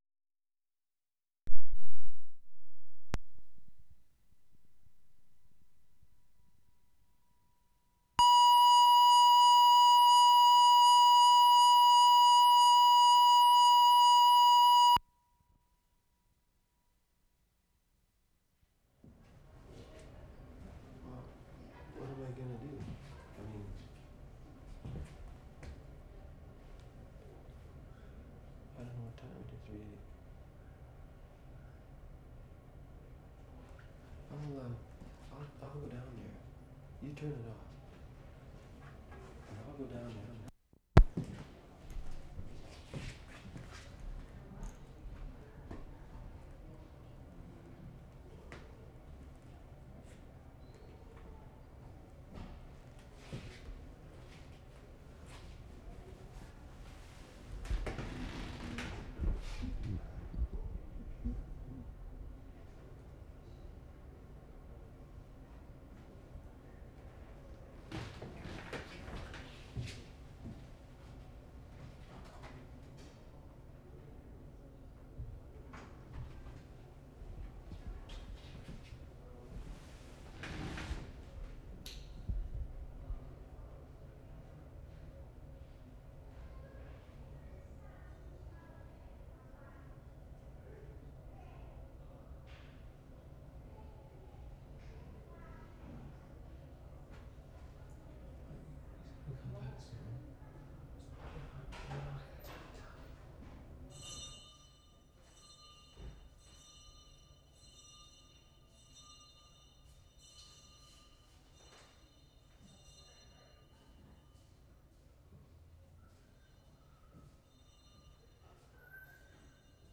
VIKING, ALBERTA Sept. 25, 1973
DINNER BELL (old school bell)
1. Bell slowly approaching, distortions on left channel as it comes close (2'30").